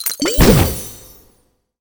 potions_mixing_alchemy_04.wav